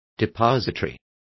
Complete with pronunciation of the translation of depository.